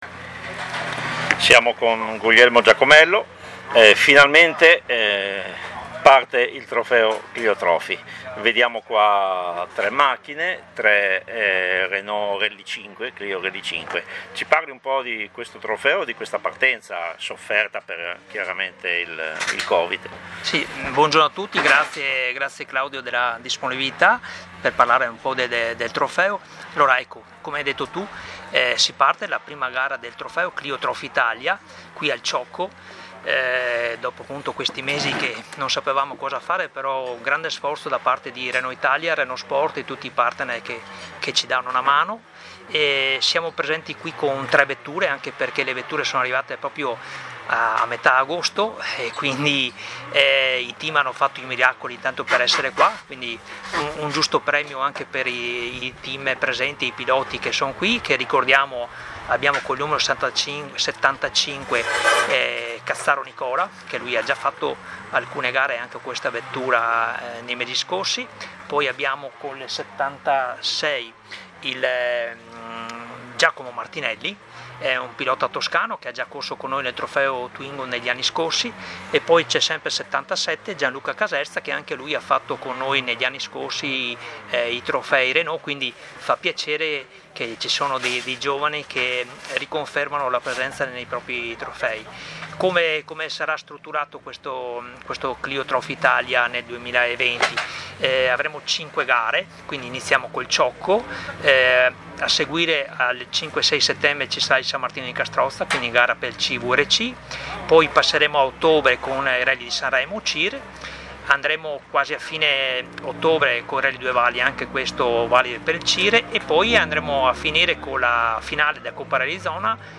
Interviste pre-gara